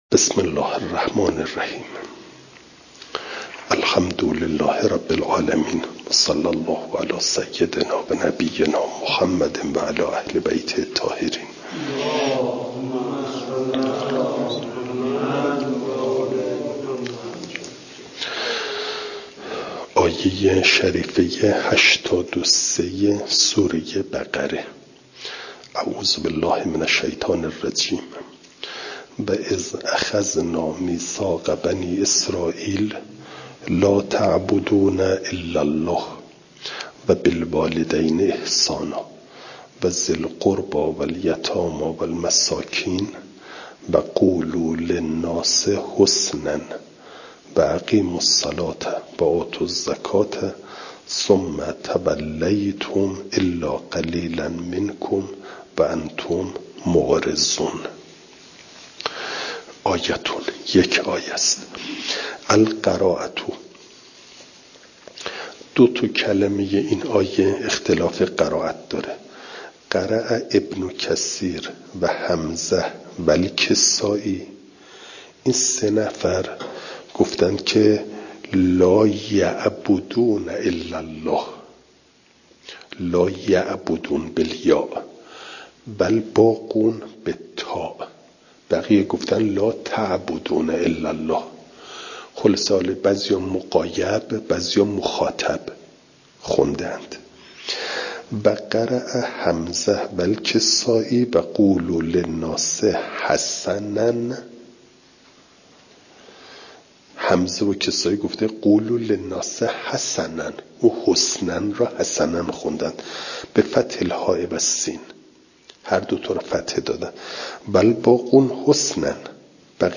فایل صوتی جلسه هشتاد و نهم درس تفسیر مجمع البیان